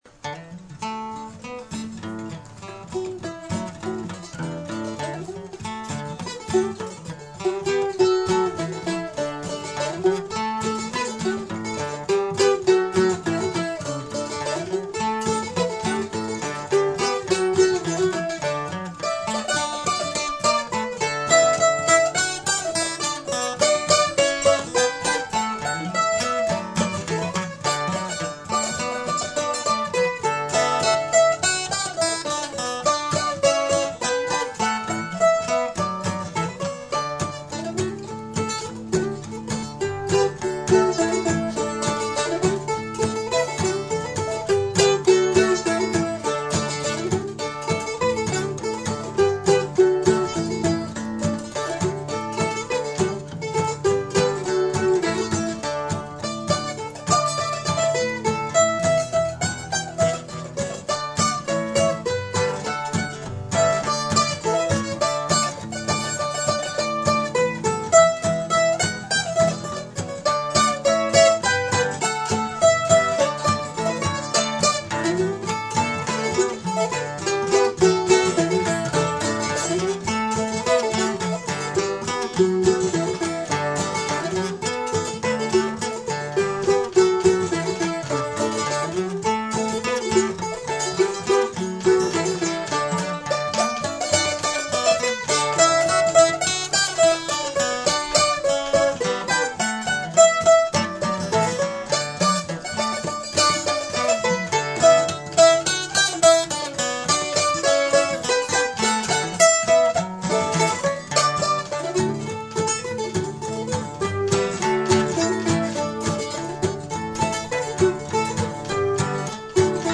2017 Here’s a compilation of some of the songs we played. 30 minutes long. Mono. Another fly-by-the-pants cell phone recording.